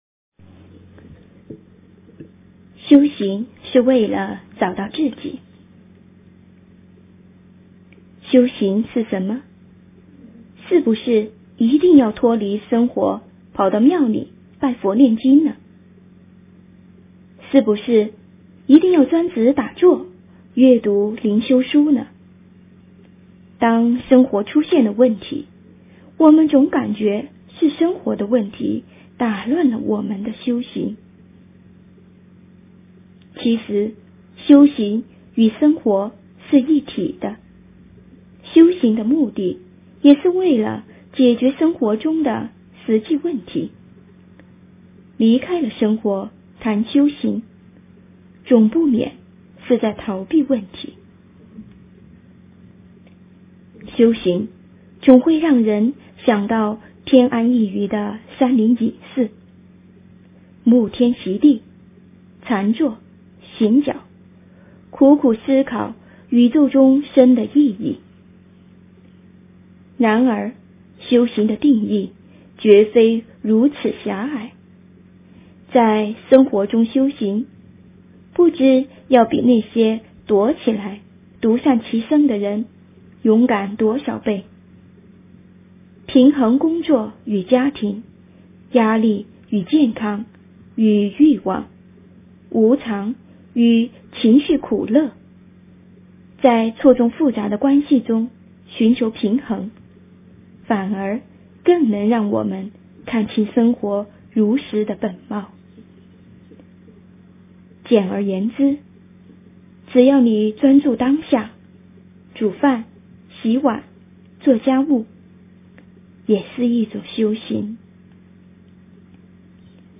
修行是为了找到自己 - 诵经 - 云佛论坛